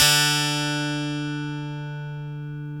GTR EL-AC102.wav